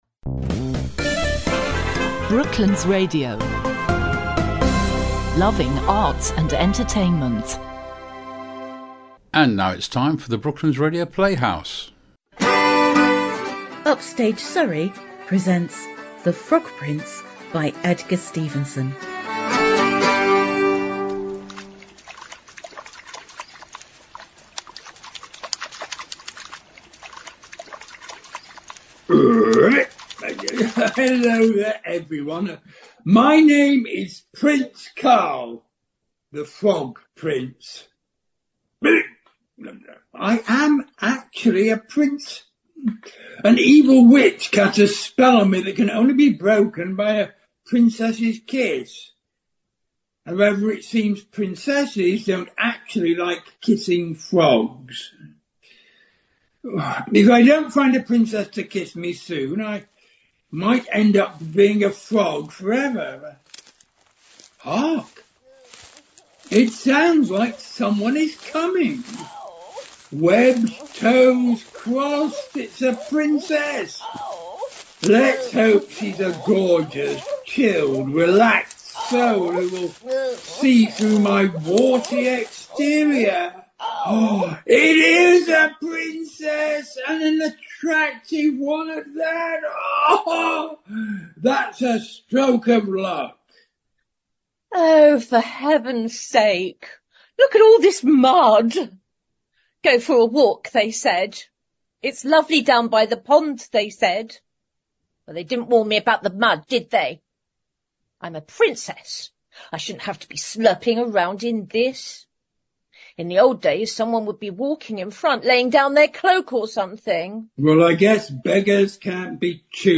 The talented team at Upstage Surrey recorded The Frog Prince for Brooklands Radio.